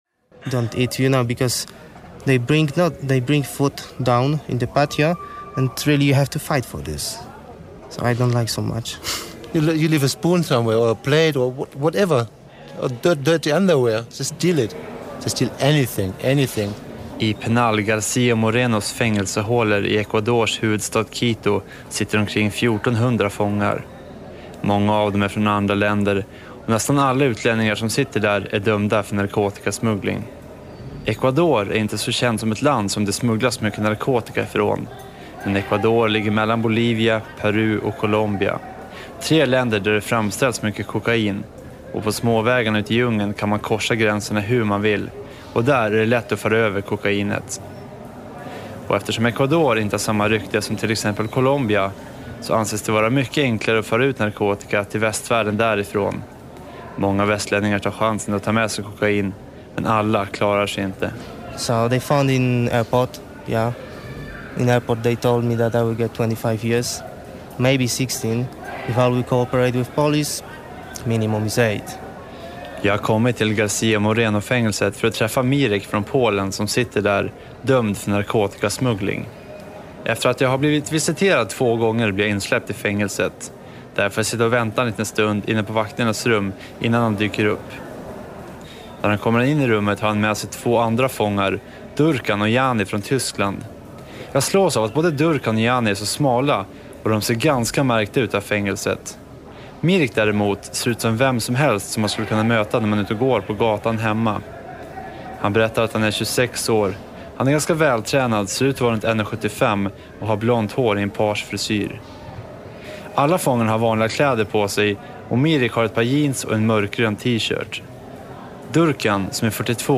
Livet i fängelset är extremt tufft och varje dag är en kamp där fångarna bokstavligen tvingas slåss för att få mat och överleva. Reportaget sändes i P3-programmet…